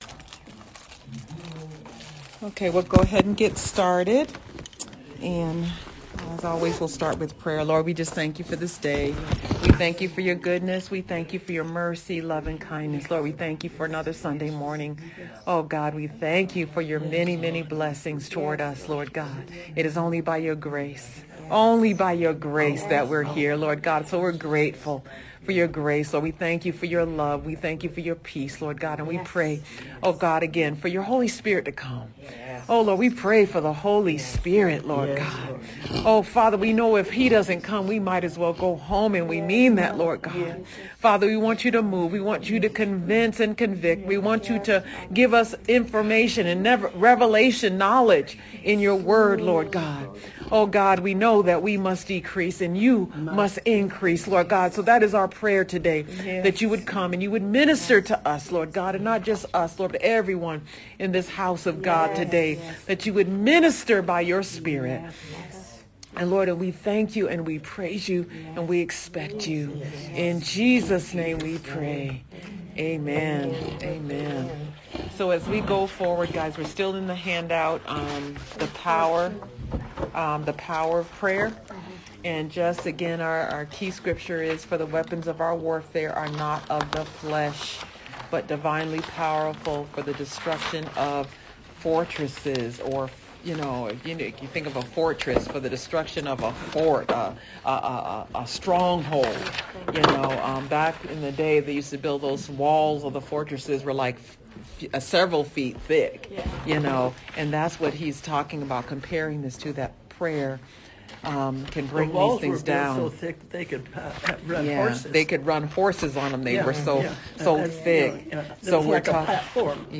Battle Plan for Prayer Audio Class